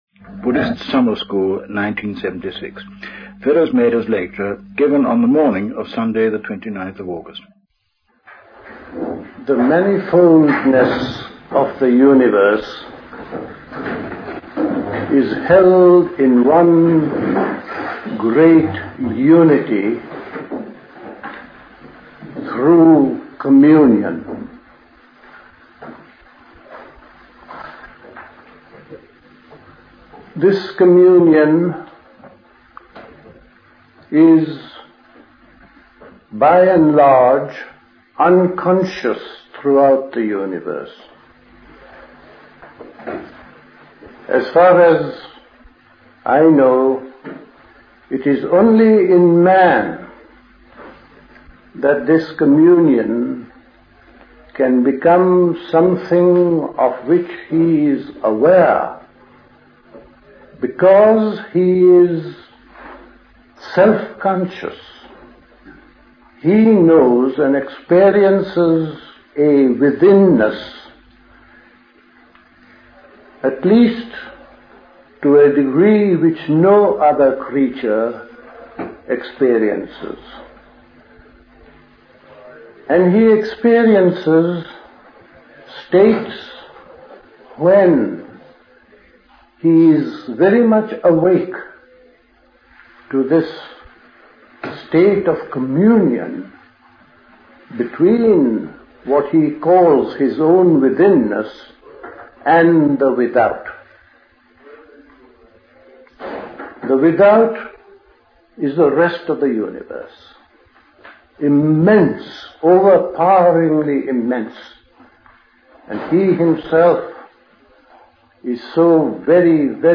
A talk
at High Leigh Conference Centre, Hoddesdon, Hertfordshire